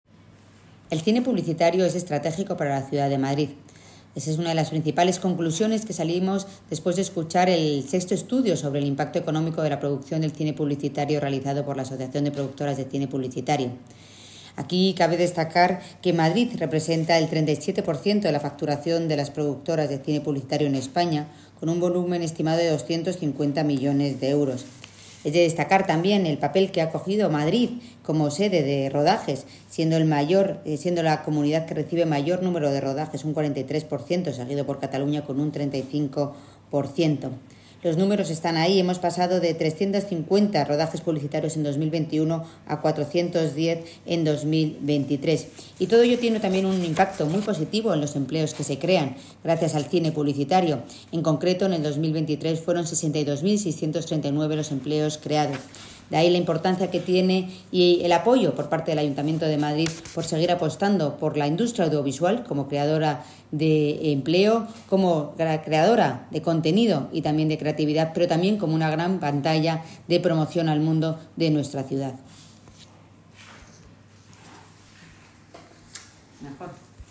Nueva ventana:Intervención de Maíllo en la presentación del VI Estudio sobre el impacto económico de la producción de cine publicitario